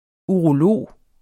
Udtale [ uʁoˈloˀ ]